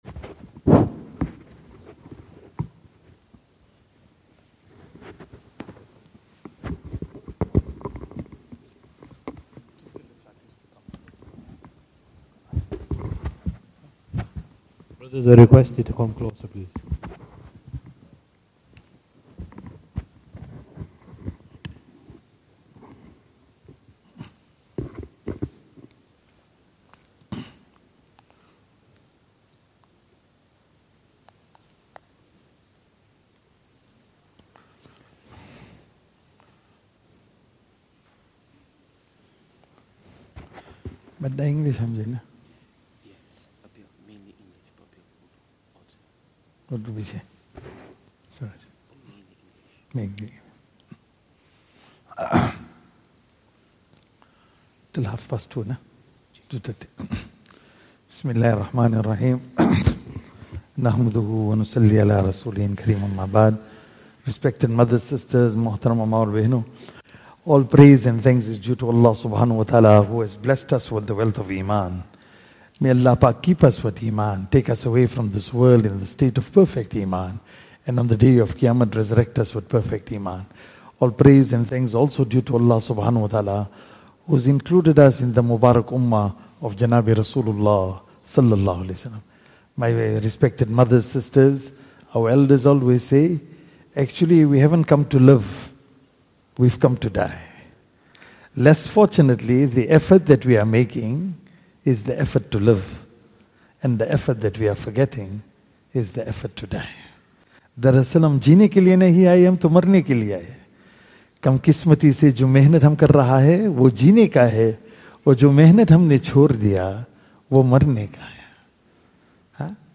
Zakariyya Jaam'e Masjid, Bolton